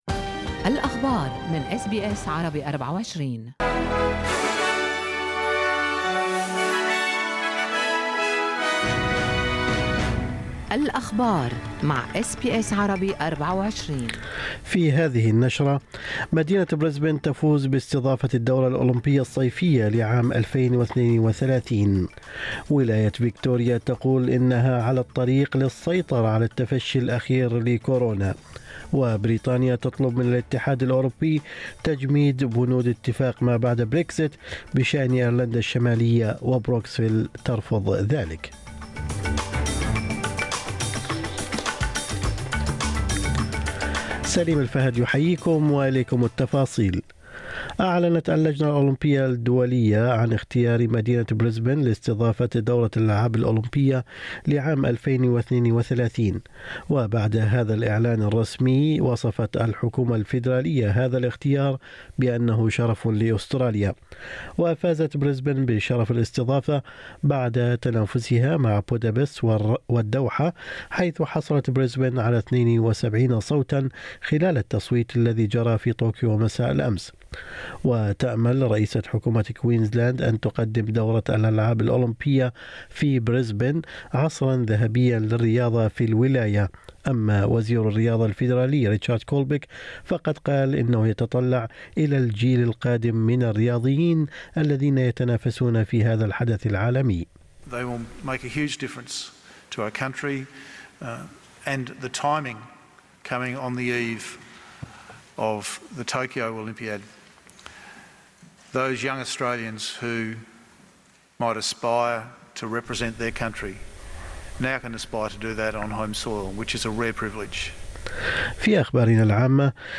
نشرة أخبار الصباح 22/7/2021